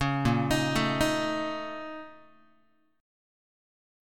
BbmM11 Chord